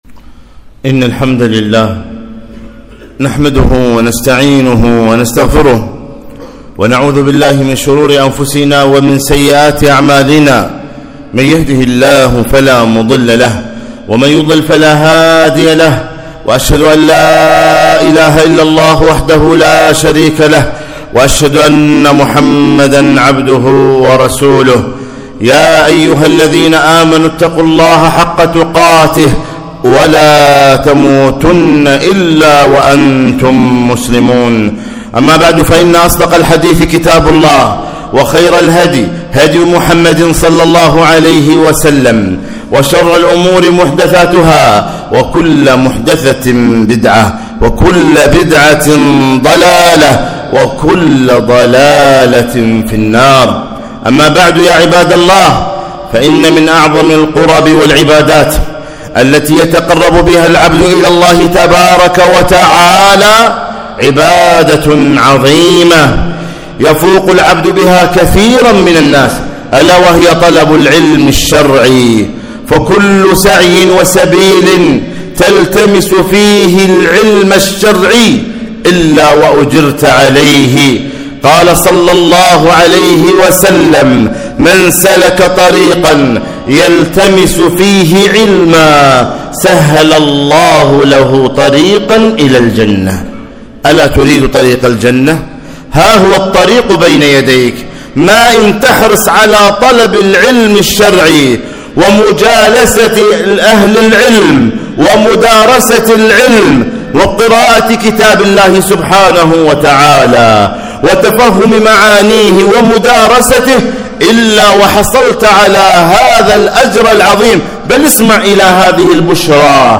خطبة - لا تحرم نفسك العلم